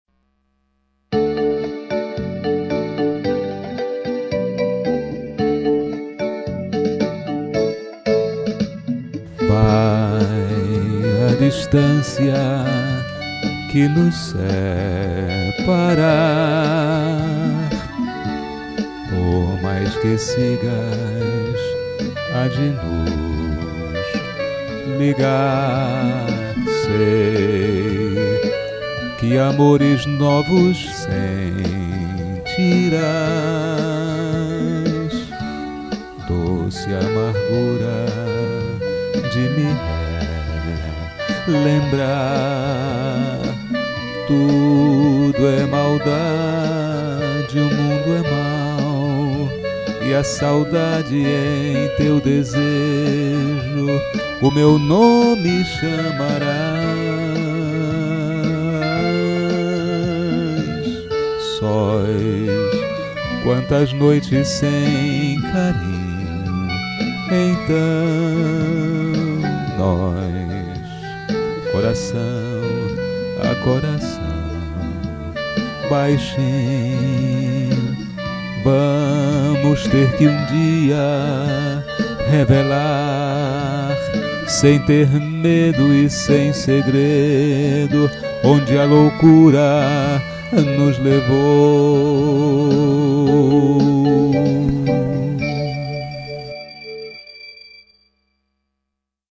teclado